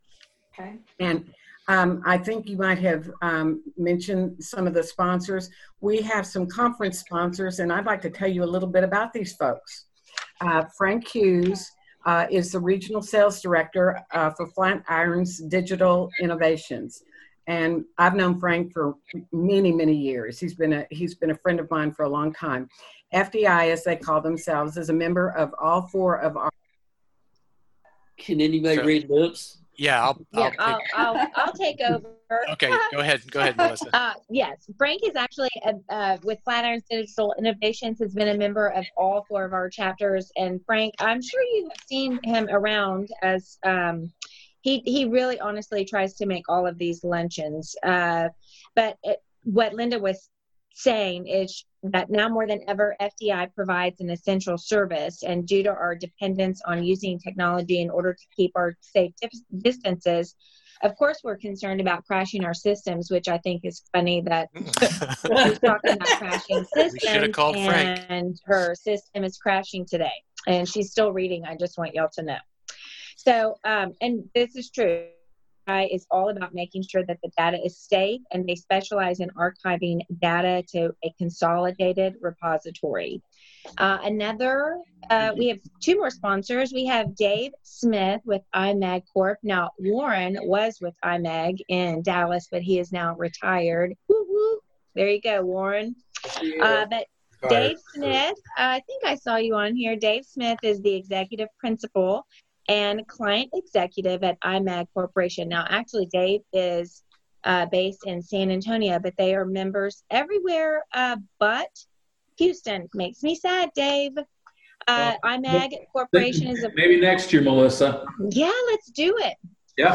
Video Conference with Crown Scientific